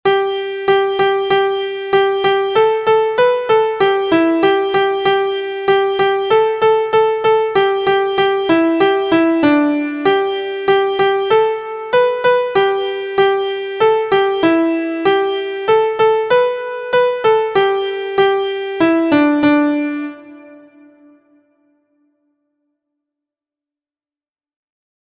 • Origin: Virginia, USA – Folk Song
• Key: G Major
• Time: 4/4
• Pitches: beginners: So La Do Re Mi – pentatonic scale
• Musical Elements: notes: quarter, eighth; introducing the intervals Do\La and La/Do, tune sounds minor by ending phrase on the 6th (La)